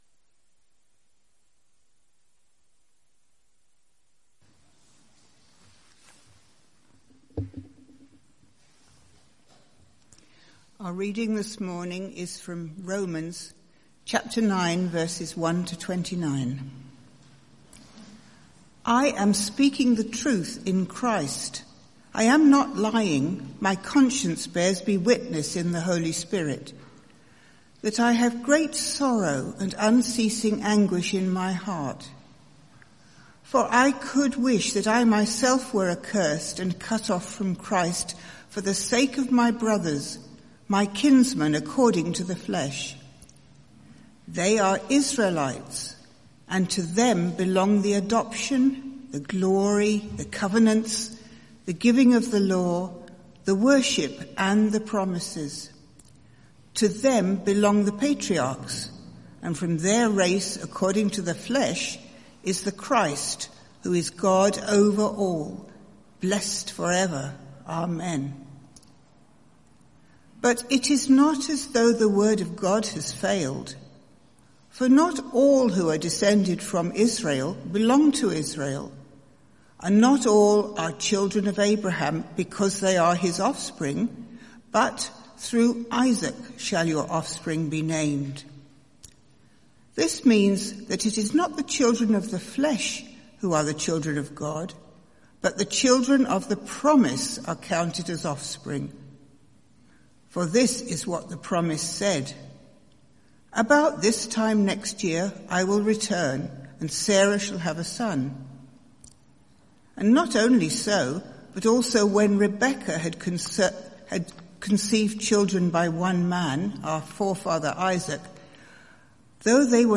Sermon Series: The 5 Solas of the Reformation